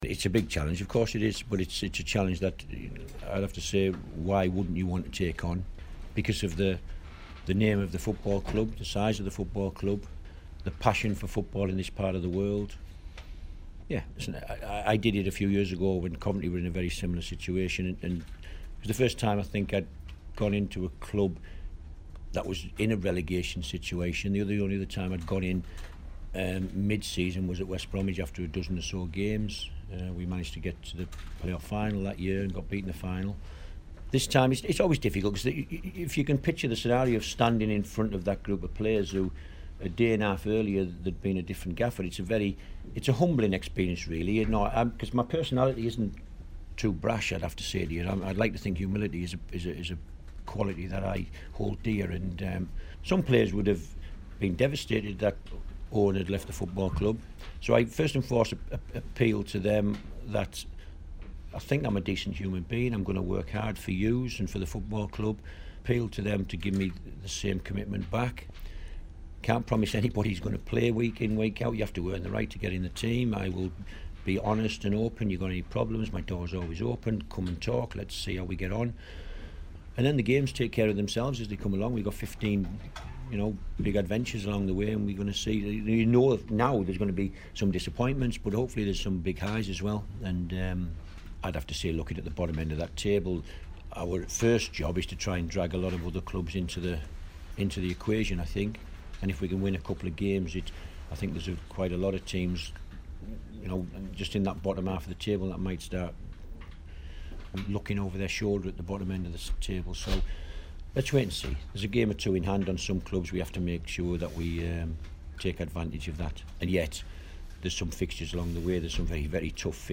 Tony Mowbray interview